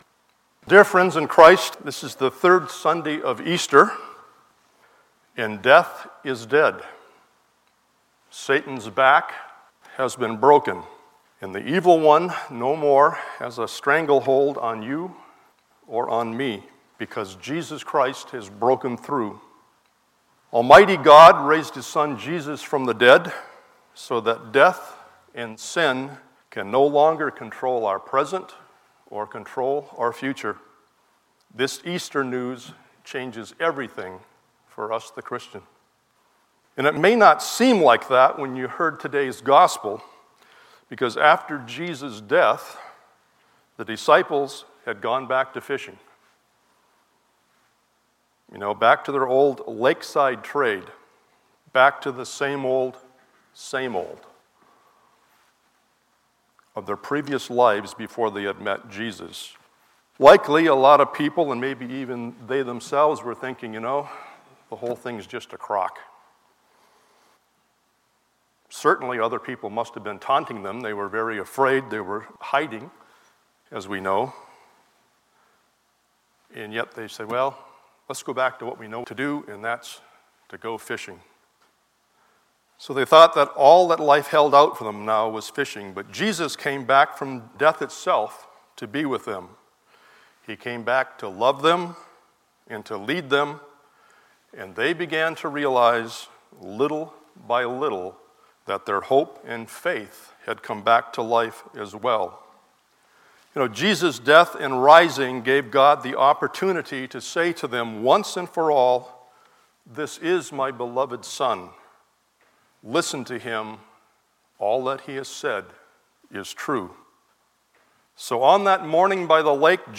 Sermon for the 3rd Sunday in Easter: